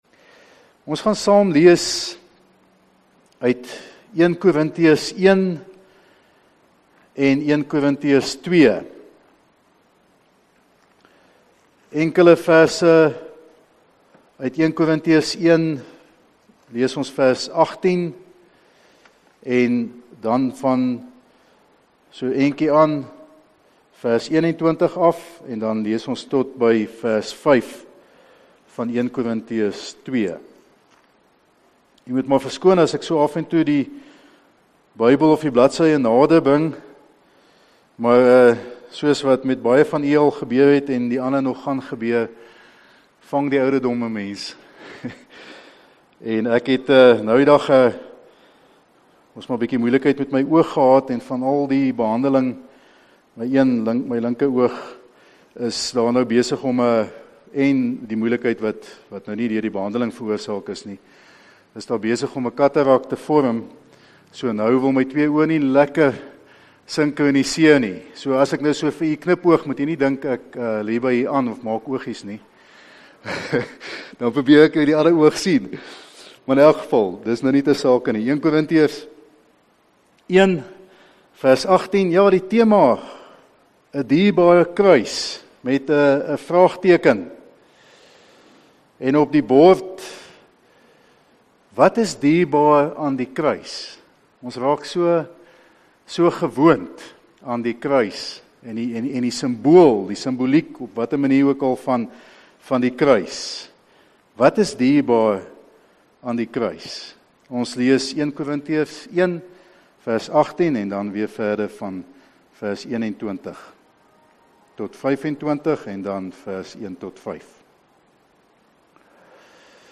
Erediens - 14 Maart 2021